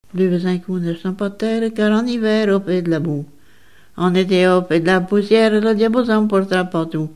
bachique
Genre strophique
Pièce musicale inédite